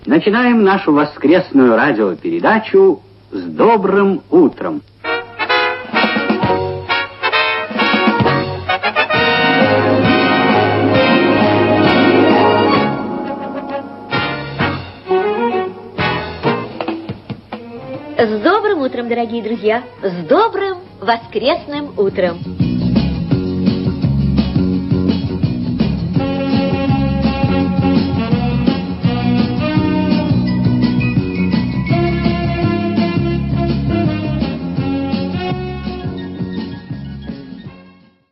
Воскресная передача С Добрым утром в 9 часов 45 минут